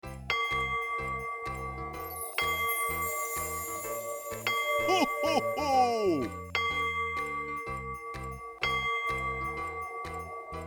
cuckoo-clock-05.wav